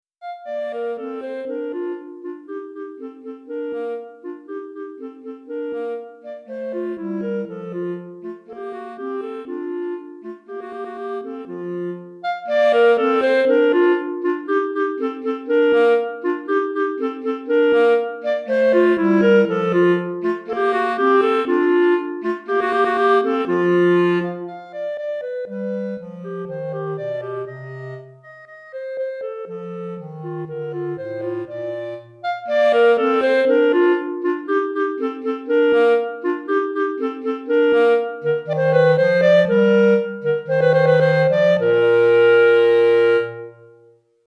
Clarinette en Sib et Clarinette Basse